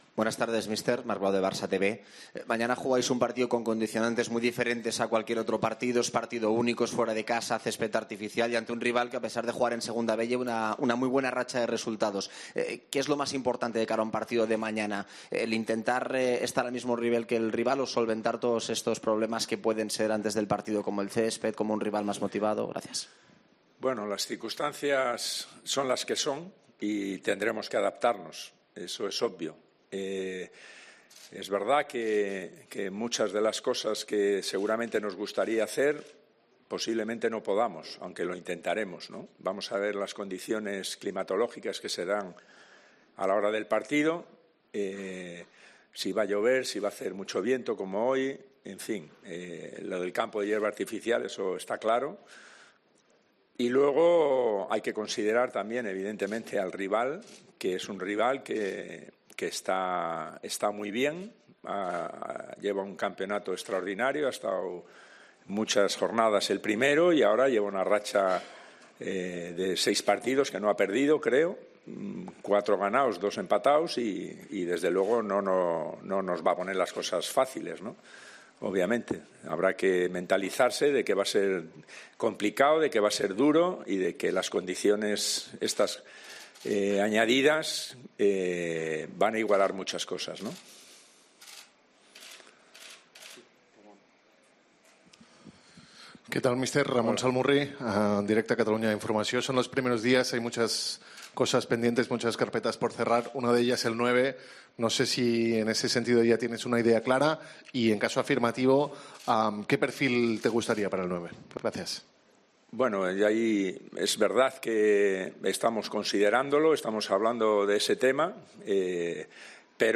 El entrenador del Barcelona, Quique Setién, ha atendido a los medios en la previa del encuentro que medirá al club blaugrana con el Ibiza este miércoles en los dieciseisavos de final de la Copa del Rey.